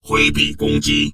文件 文件历史 文件用途 全域文件用途 Enjo_skill_02_3.ogg （Ogg Vorbis声音文件，长度1.1秒，125 kbps，文件大小：17 KB） 源地址:地下城与勇士游戏语音 文件历史 点击某个日期/时间查看对应时刻的文件。